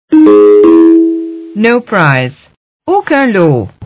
Whenever a ticket is checked by a retailer at a lottery terminal, you will see a video message on the Customer Display Screen and hear a winning or non-winning jingle with a voice-over message.
Non-winning message:
1. You’ll see a non-winning message on the Customer Display Screen and hear a non-winning message play from the lottery terminal.